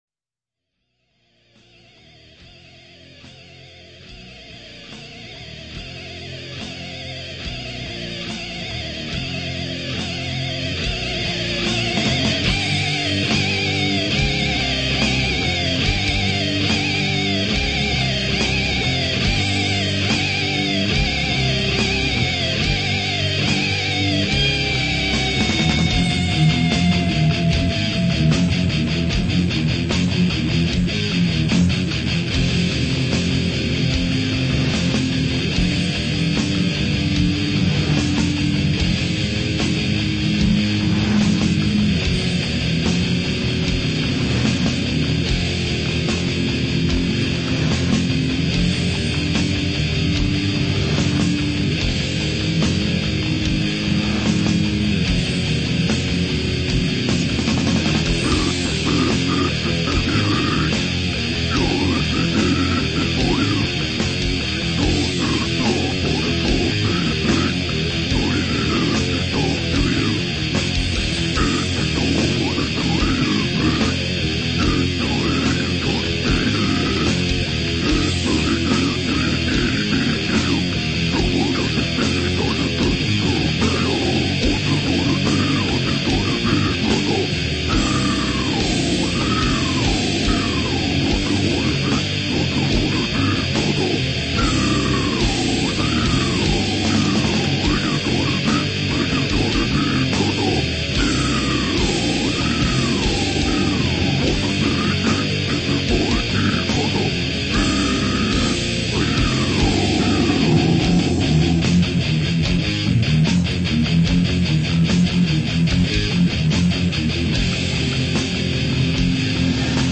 Список файлов рубрики METAL
brutal death